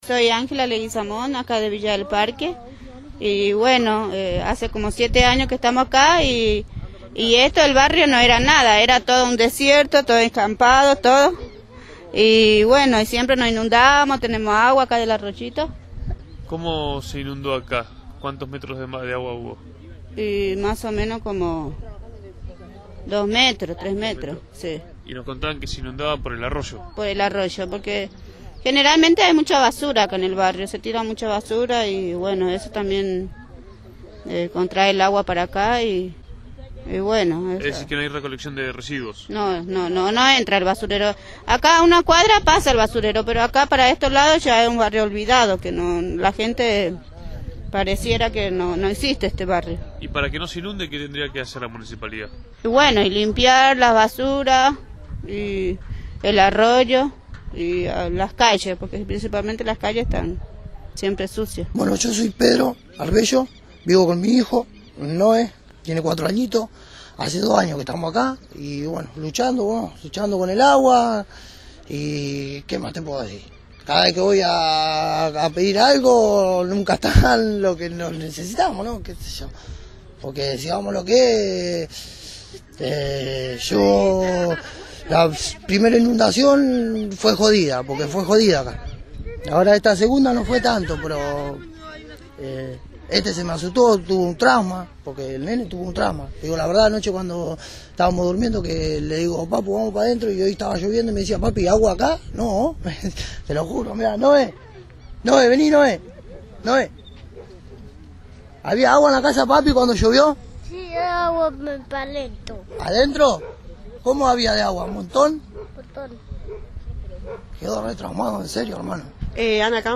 Aquí el testimonio de los vecinos de Villa del Parque.
vecinos-villa-del-parque-lujan.mp3